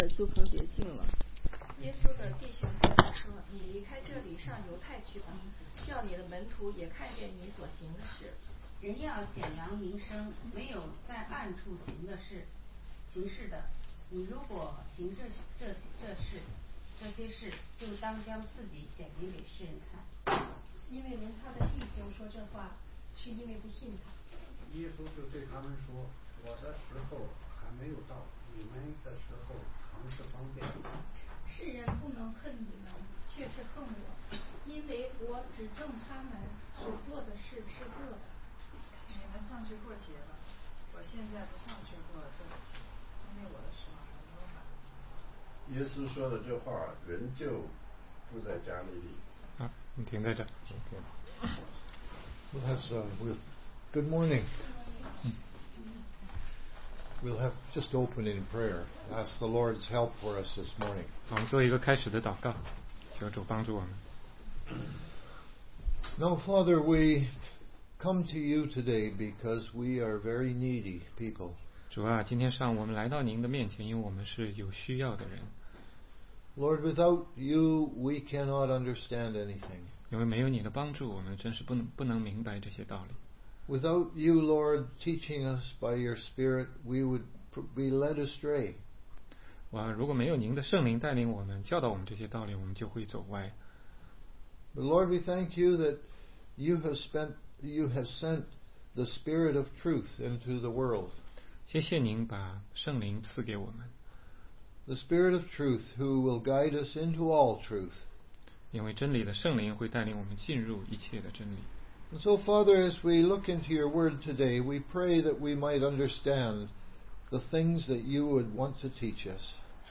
16街讲道录音 - 约翰福音7章1-5节